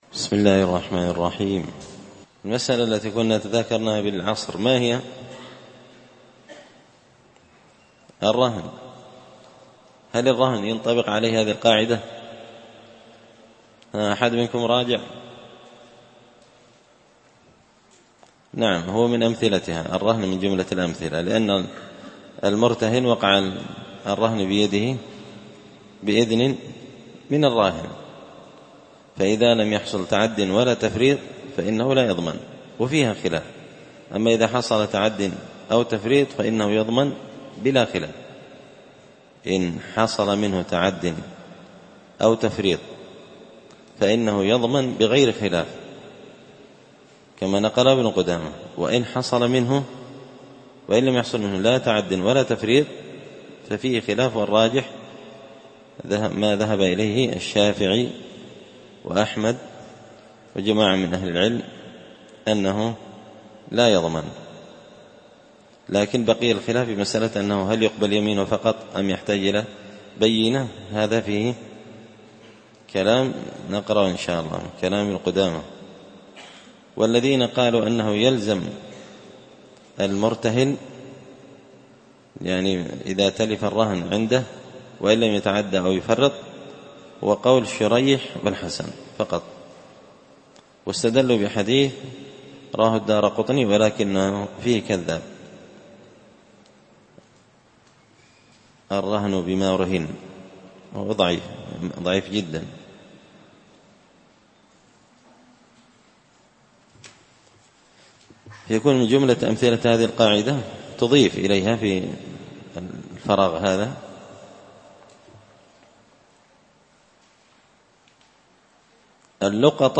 مسجد الفرقان